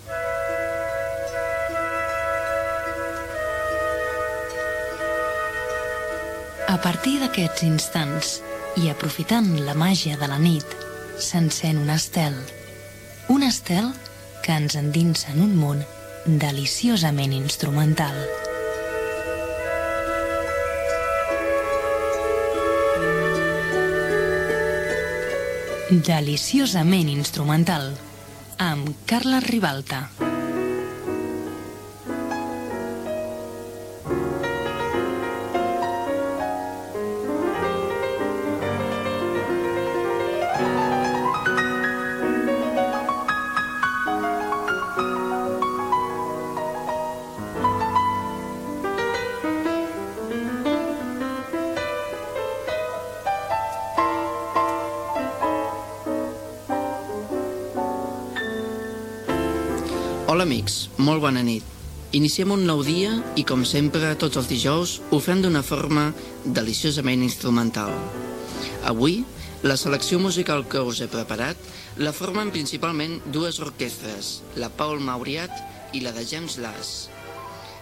Careta del programa i presentació
Musical
FM